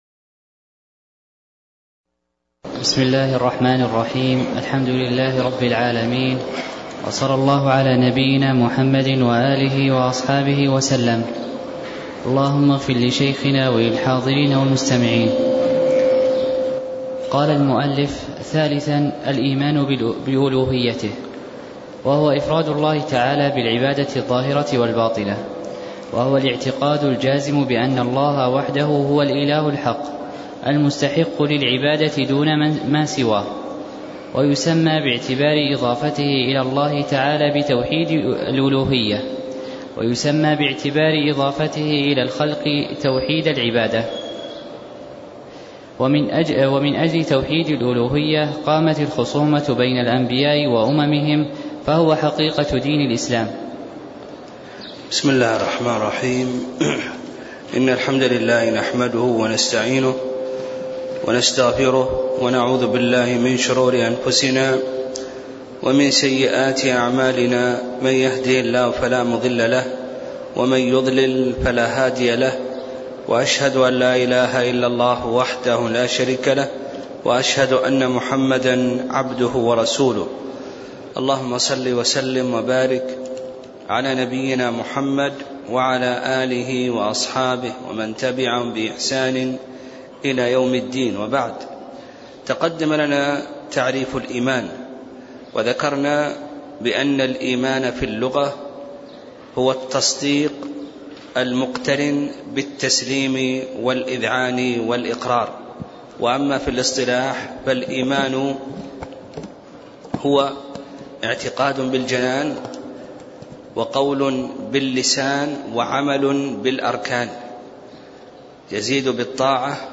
تاريخ النشر ٢٣ شعبان ١٤٣٦ هـ المكان: المسجد النبوي الشيخ